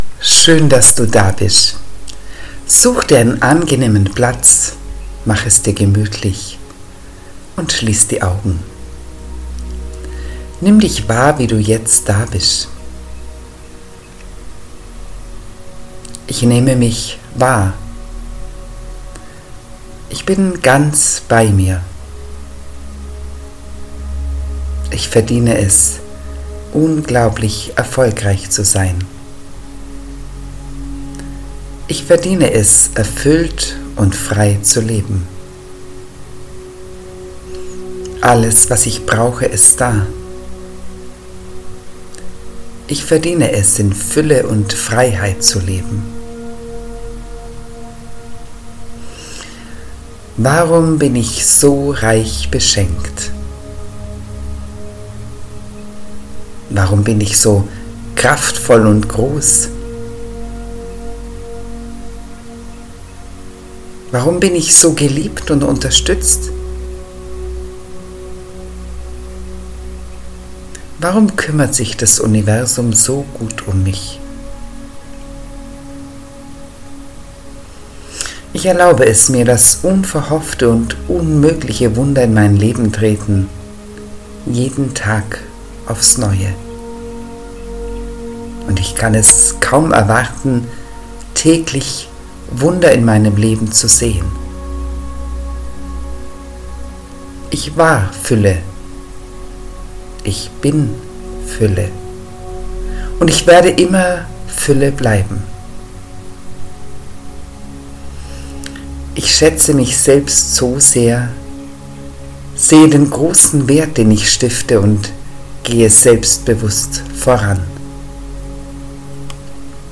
Fuelle-Meditation_mit_-Musik-2.mp3